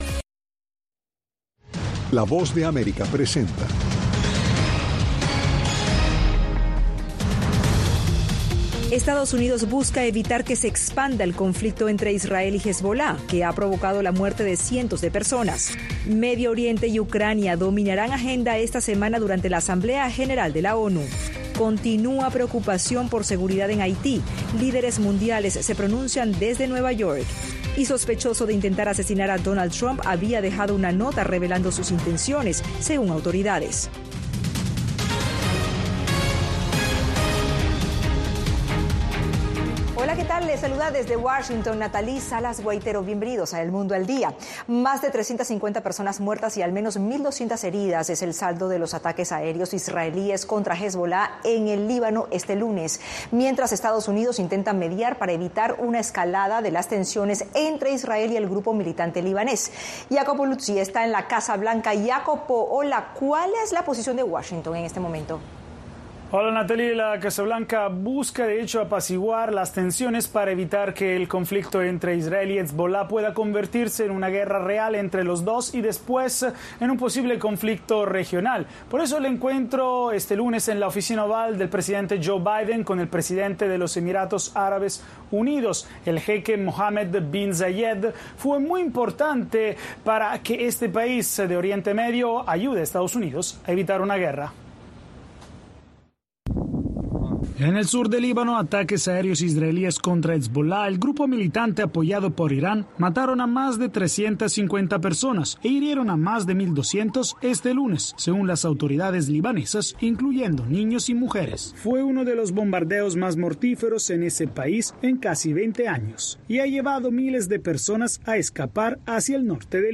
Un noticiero con información diaria de Estados Unidos y el mundo.